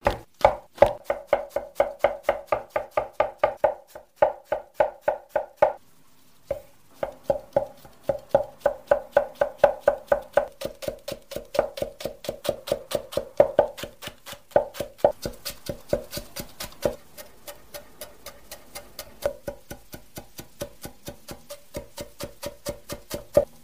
Tiếng Cắt, Thái Bắp cải, Hành lá, Cộng sả, Rau củ quả… (Full)
Thể loại: Tiếng ăn uống
Description: Tiếng chặt, tiếng bổ, tiếng xắt, tiếng thái mỏng, tiếng đập thớt, tiếng chẻ rau củ, tiếng gõ dao, tiếng xắt nhỏ... là những âm thanh đa dạng và phong phú, mang đến cảm giác sinh động và chân thực trong các cảnh quay nấu ăn hay phim tài liệu ẩm thực.
tieng-cat-thai-bap-cai-hanh-la-cong-sa-rau-cu-qua-full-www_tiengdong_com.mp3